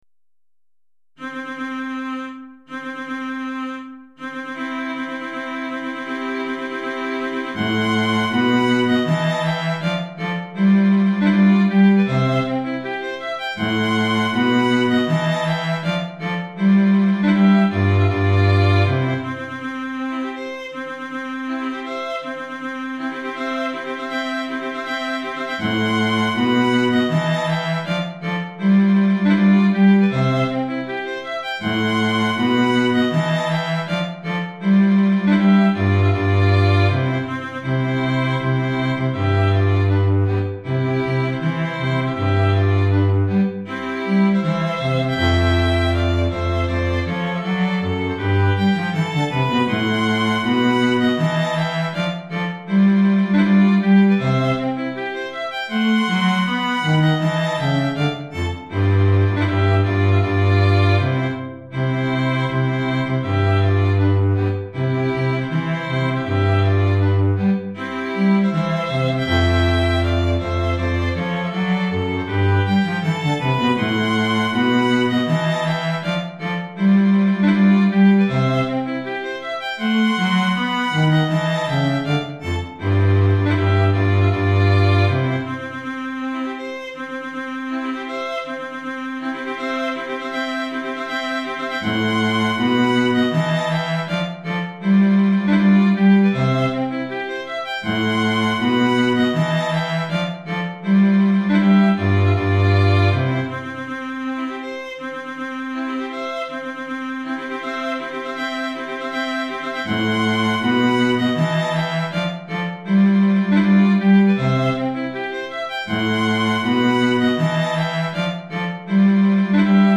Trio à Cordes